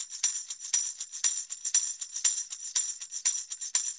Pandereta_ ST 120_2.wav